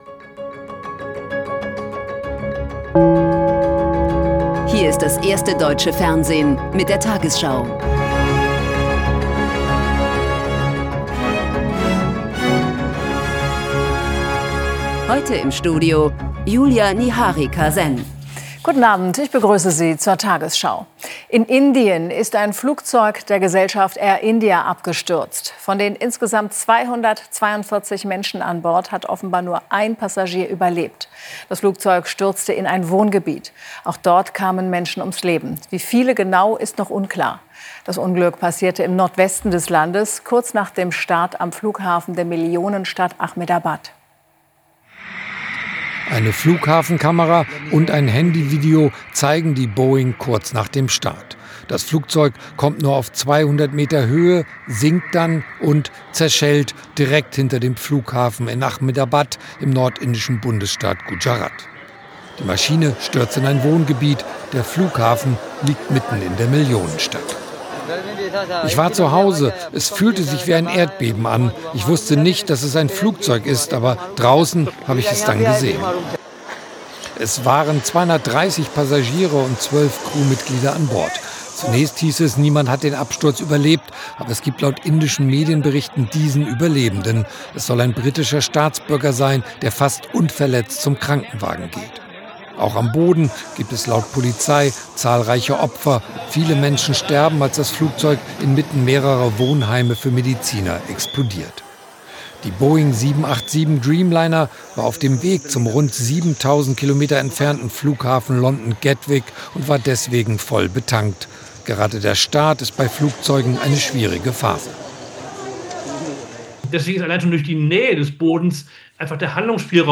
Die 20 Uhr Nachrichten von heute zum Nachhören.
Die tagesschau ist Deutschlands erfolgreichste Nachrichtensendung im Fernsehen.